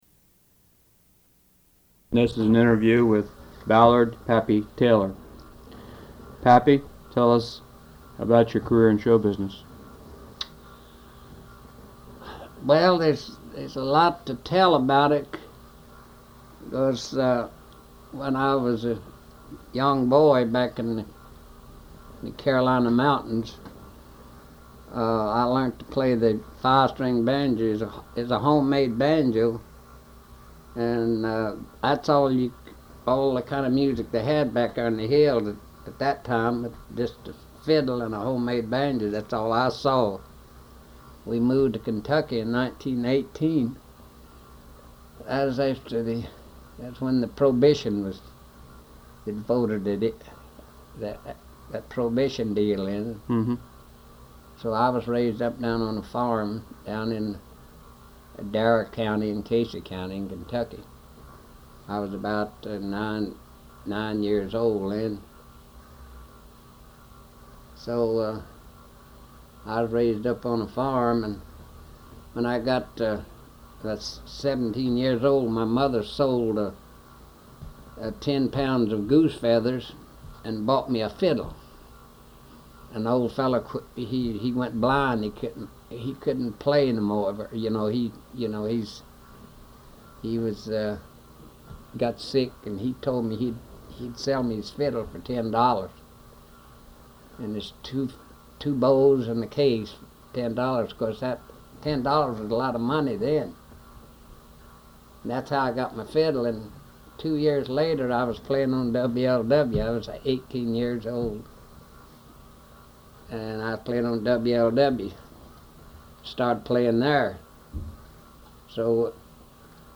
Oral Histories
Listen to history with our oral histories.